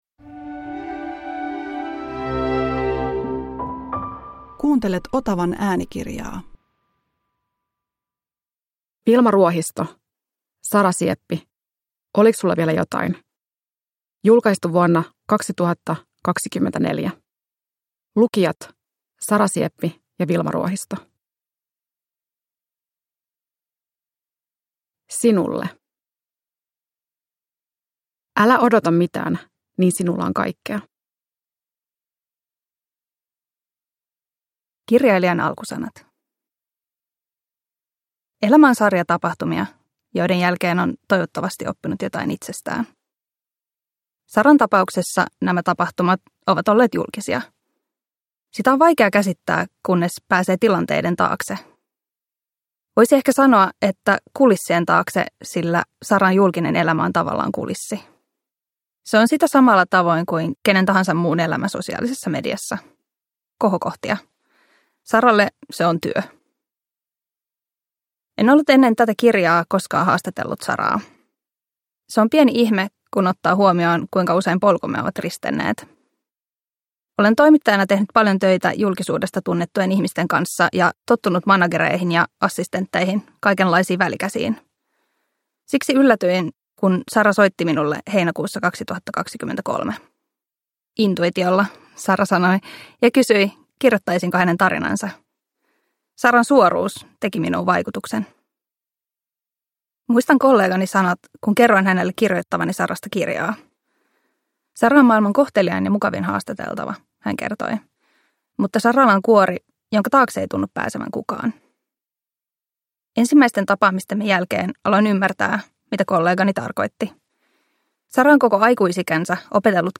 Sara Sieppi – Ljudbok